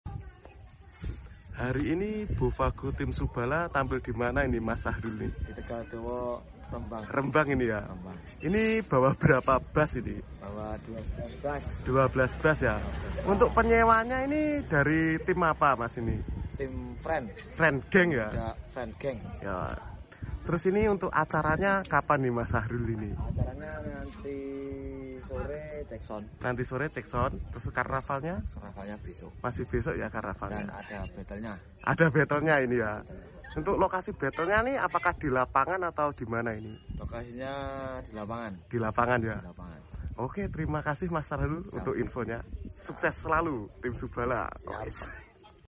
Bofago subala karnaval tegaldowo rembang